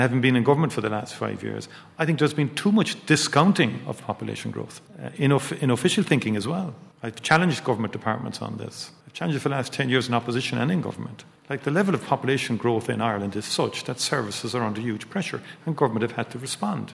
Taoiseach Michael Martin says they need to be better prepared for an increased population than they have been in the past: